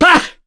Ricardo-Vox_Attack1.wav